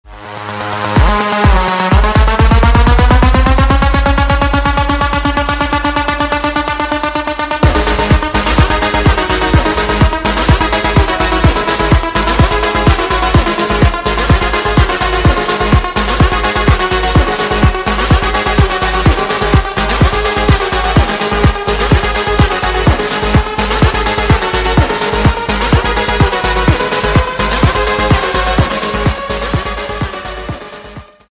Громкие Рингтоны С Басами » # Рингтоны Без Слов
Рингтоны Электроника